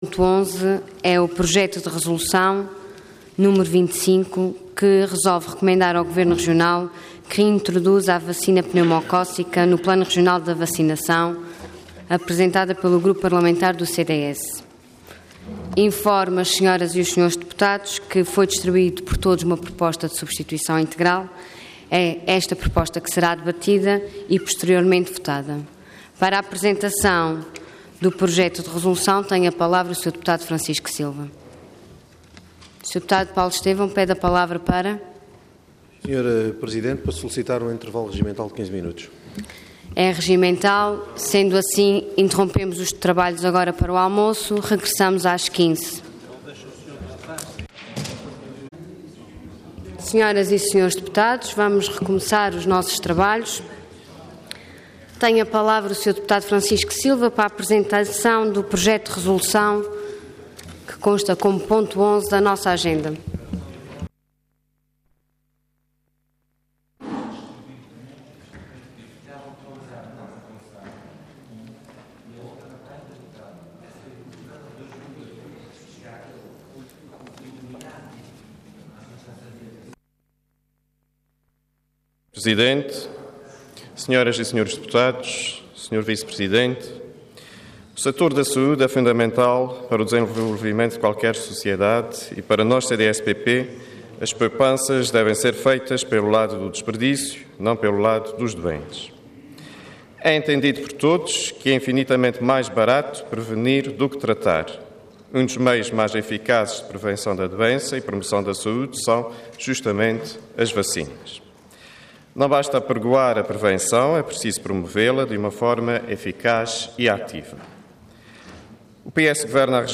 Intervenção Projeto de Resolução Orador Francisco Silva Cargo Deputado Entidade CDS-PP